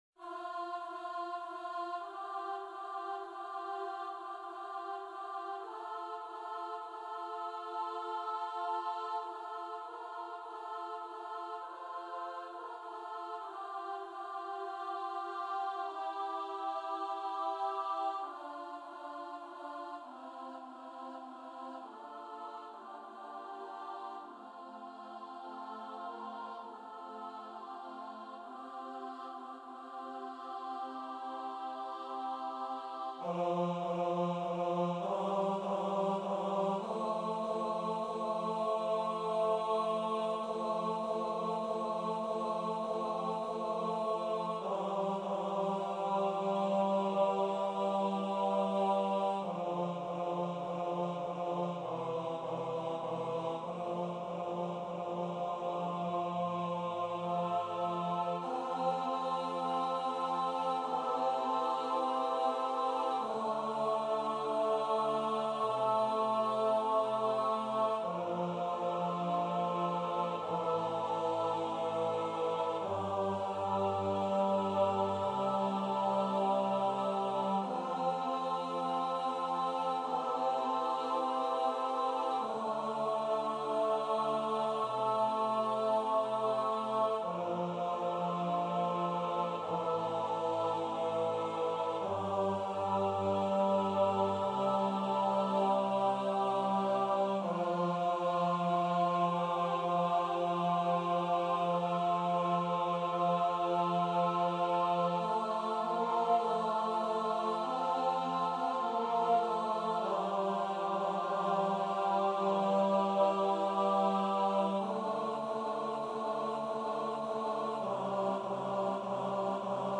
- Œuvre pour choeur à 8 voix mixtes (SSAATTBB) a capella
MP3 rendu voix synth.
Tenor 2